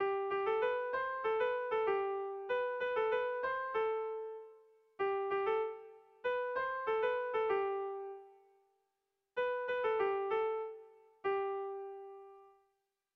Melodías de bertsos - Ver ficha   Más información sobre esta sección
Lauko txikia (hg) / Bi puntuko txikia (ip)
A1A2